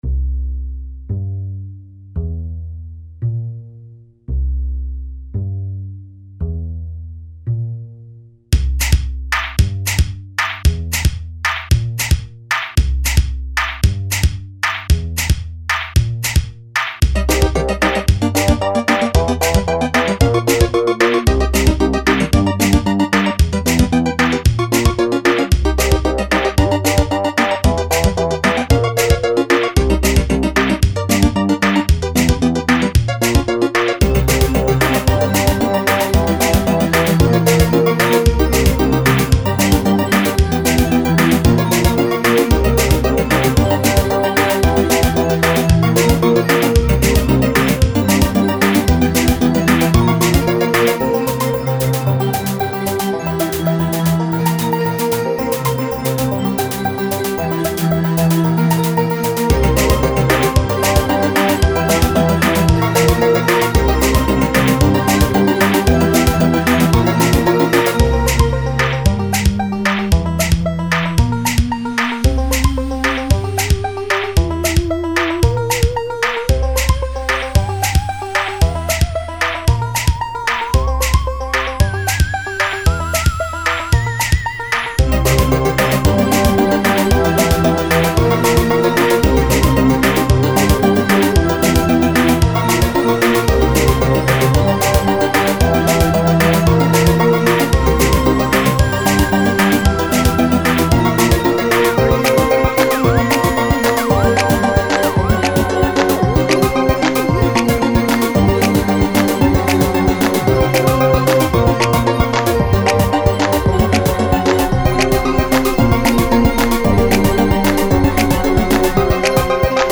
And also, I made a little electronical tune the other day: